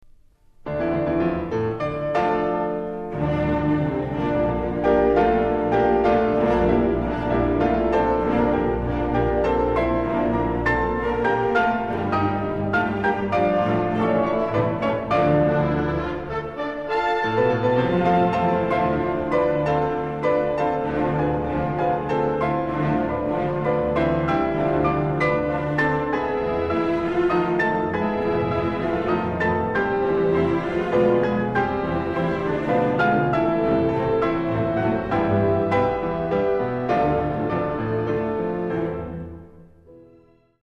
Классическая музыка